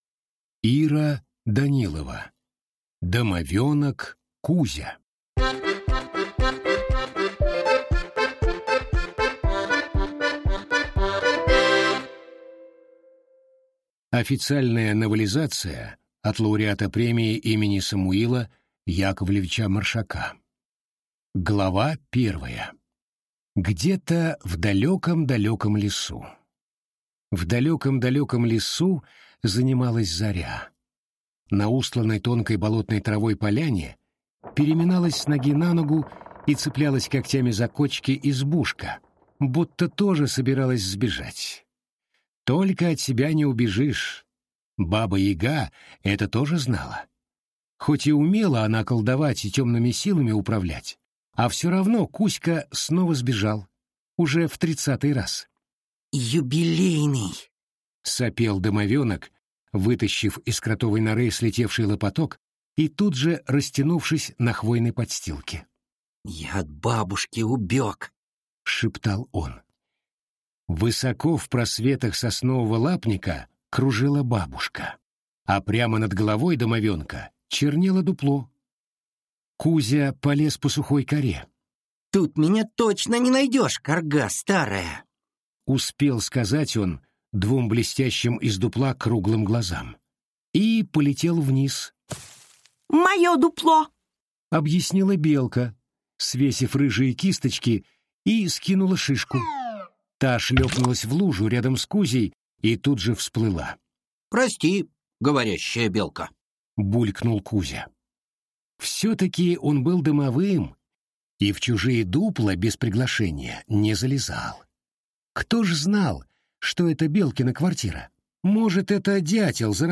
Войны Наследников (слушать аудиокнигу бесплатно) - автор Элиан Тарс